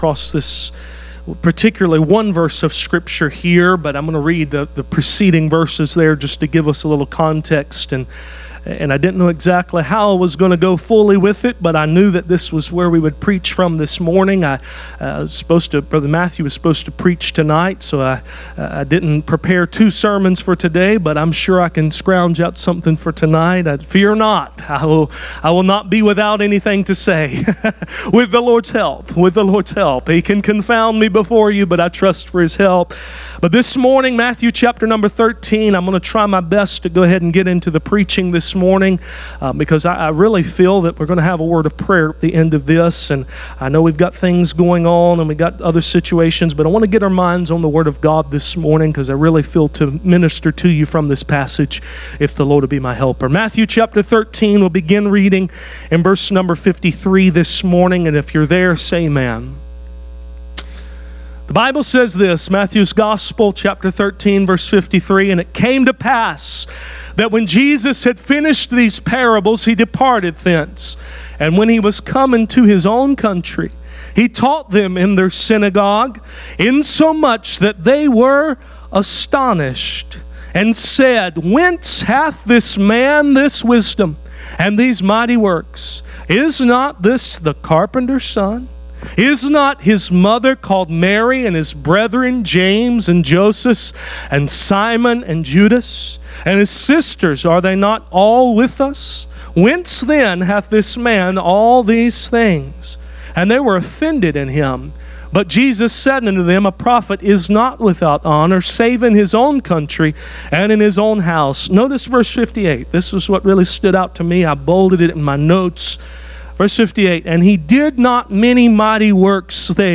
Passage: Matthew 13:53-58 Service Type: Sunday Morning « Five Anchors from Galatians